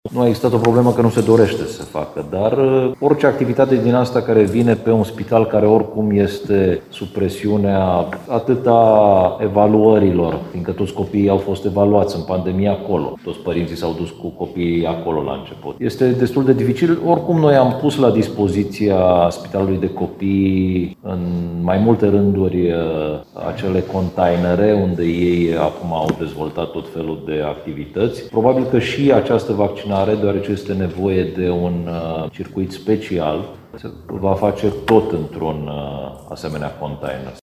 O astfel de situație este și la Spitalul de Copii „Louis Țurcanu” din Timișoara, spune subprefectul Ovidiu Drăgănescu.